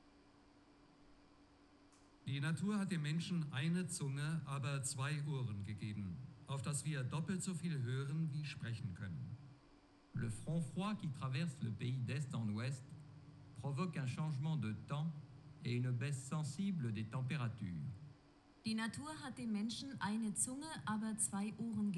peak_0g_external_Speaker_80dBSPL_PDM.wav